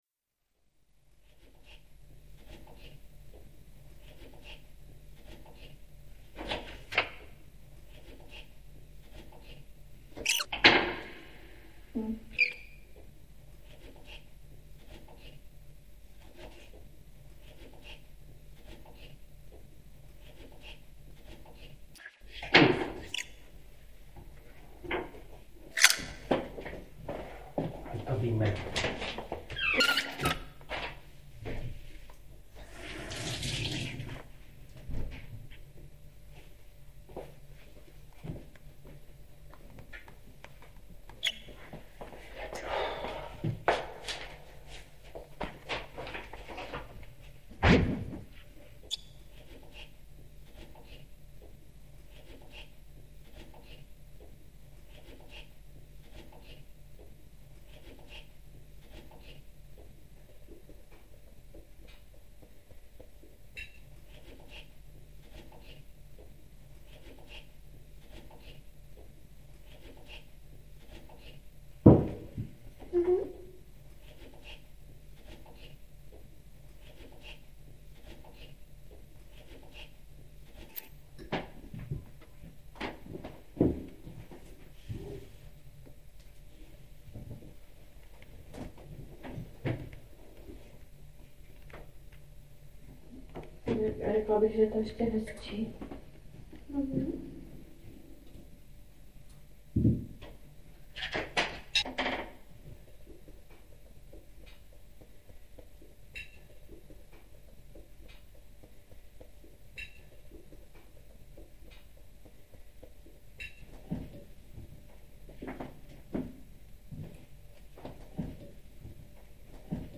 3" CD-R . manipulované mikro nahrávky